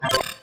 UIBeep_Open Pick Item.wav